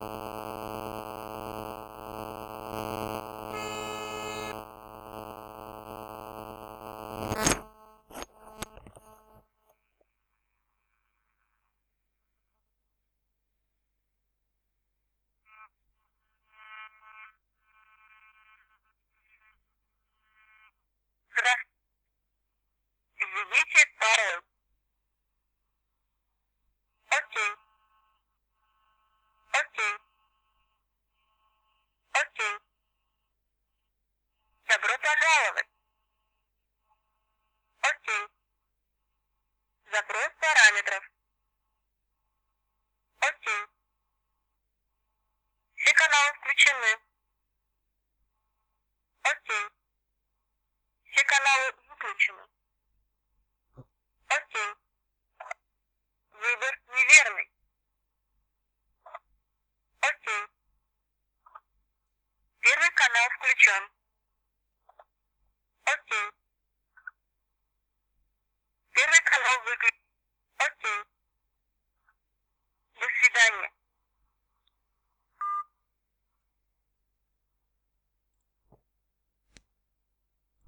Добавлены фразы микрофон включен и микрофон выключен, добавлены сигналы DTMF
Попробывал в железе ,подобрал делитель звукового модуля ,работает все нормально шумов нет голос четкий
Файл записал через громкую связь телефона далее через микрофон на компьтер, убрал только шум микрофона от звуковой платы ,так что вы услышите как бы реально на самом деле, записывалось все на одном столе,и телефон и ууик и микрофон ,отсюда и фон по вч,без внешней антенны